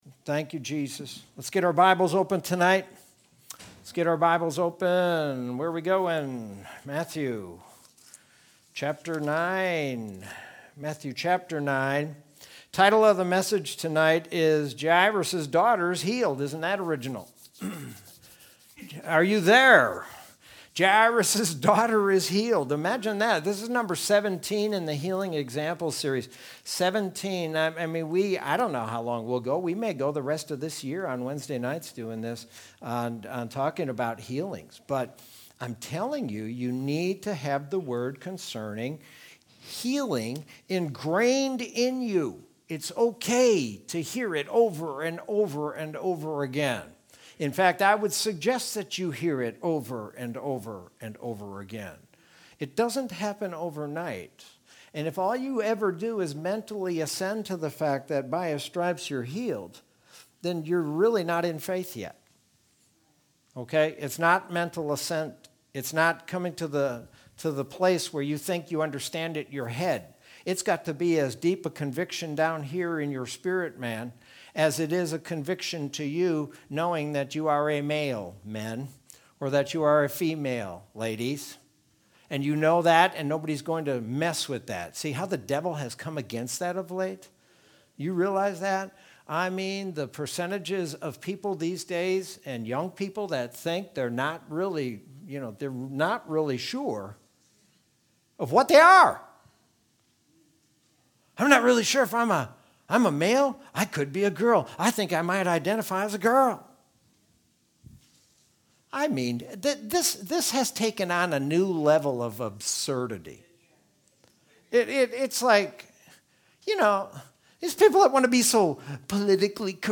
Sermon from Wednesday, June 2nd, 2021.